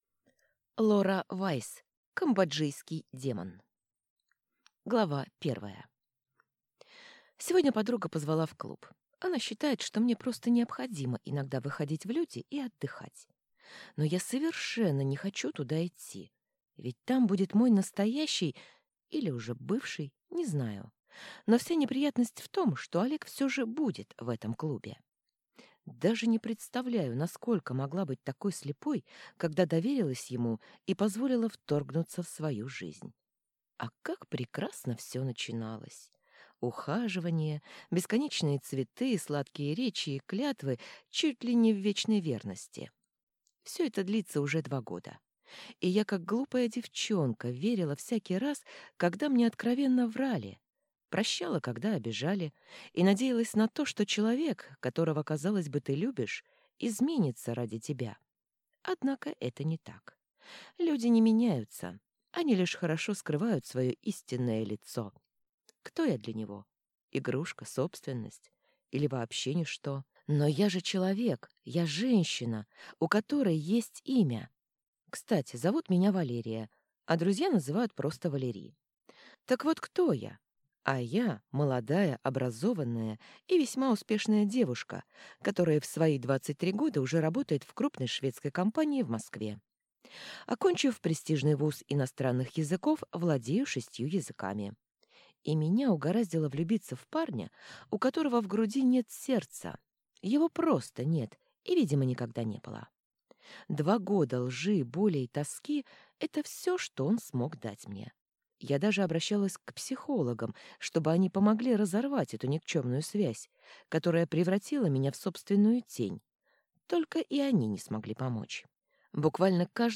Аудиокнига Камбоджийский демон | Библиотека аудиокниг